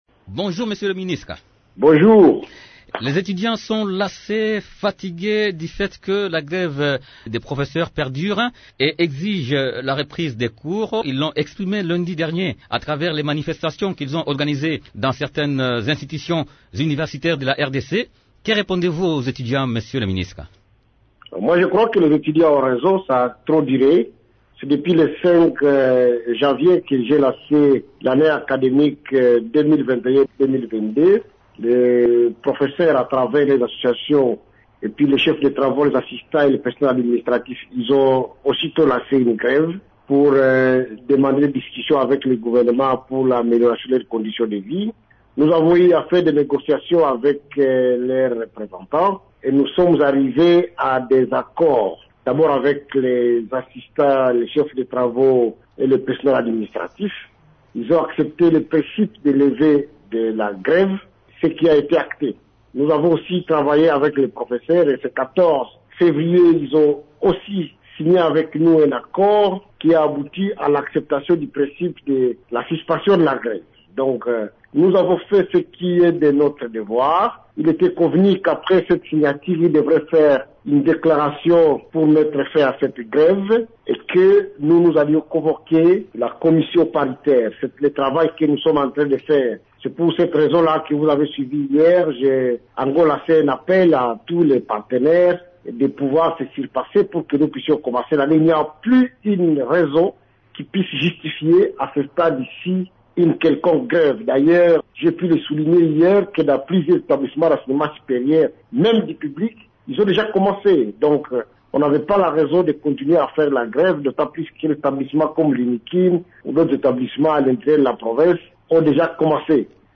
Vous pouvez écouter le ministre Muhindo Nzangi dans cet extrait sonore :